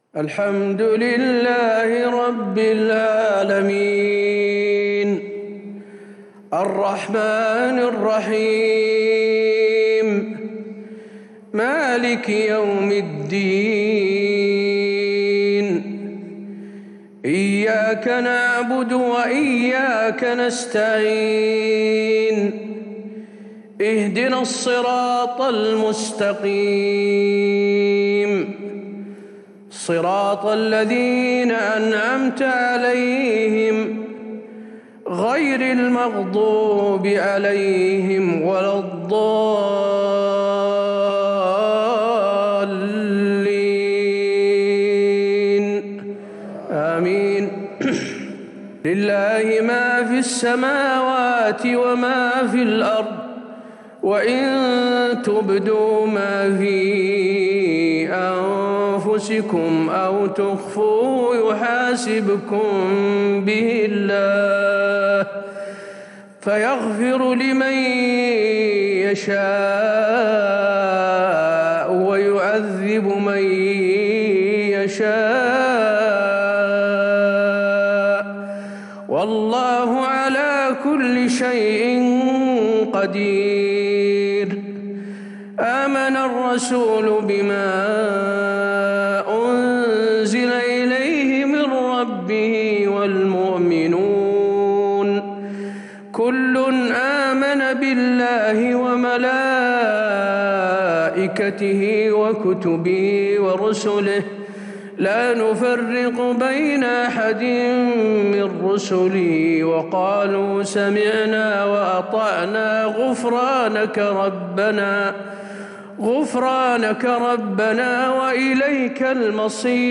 صلاة العشاء للشيخ حسين آل الشيخ 25 ربيع الآخر 1442 هـ